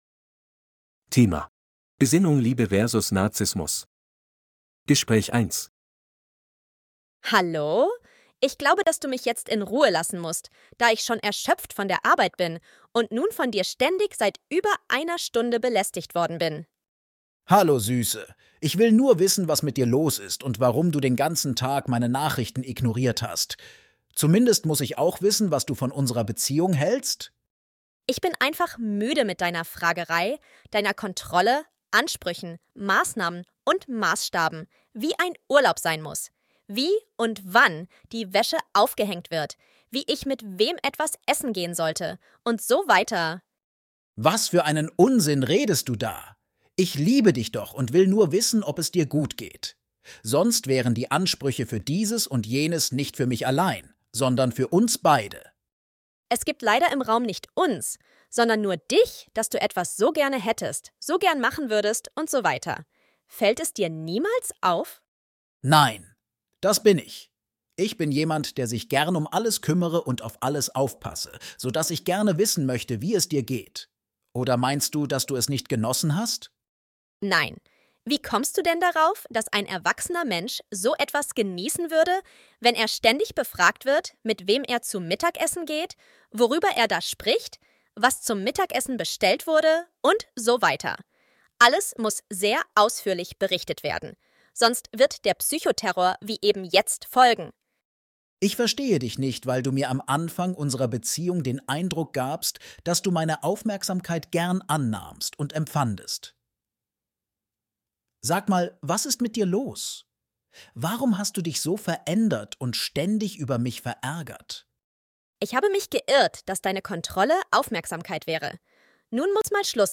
Bài nghe hội thoại 1:
B2-C1-Registeruebung-3-Besinnung-Liebe-vs.-Narzissmus-Gespraech-1.mp3